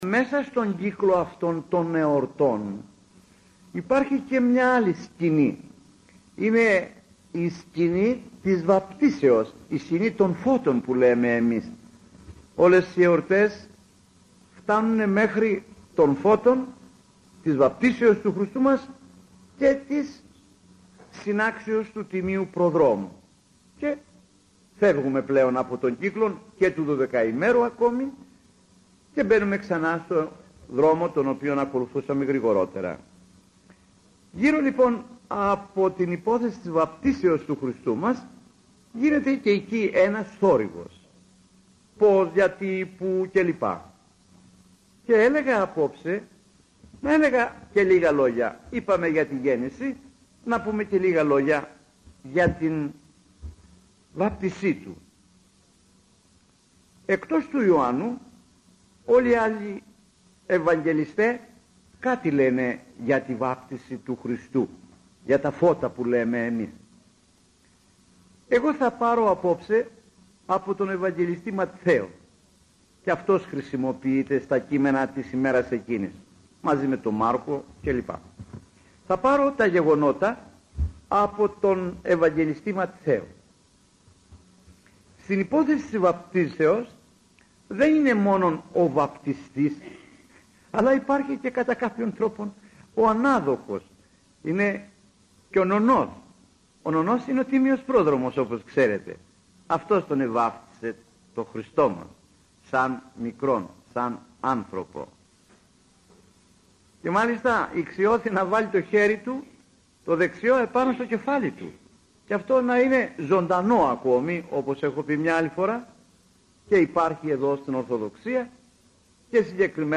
Τα Φώτα – ηχογραφημένη ομιλία